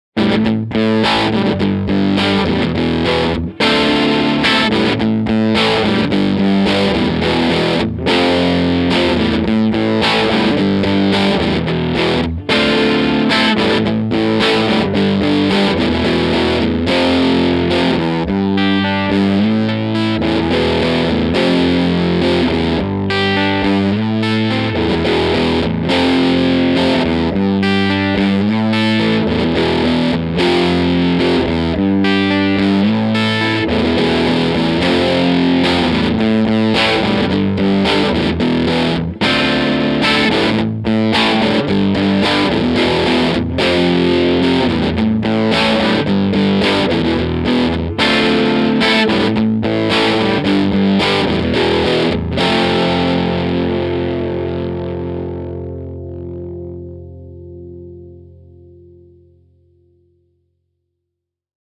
Mic was a single SM57, to Vintech (Neve-style) pre, to Apogee Rosetta 200 A/D, to the computer.
Ch.2 Dirty was as follows - no MV, cut 2:00, treble 10:30, bass 1:00, vol 10:30, munch/hi on the back, Lo input on the front.
TC15_Ch2_Dirty_LPSA_Bridge_MunchHi_LOin.mp3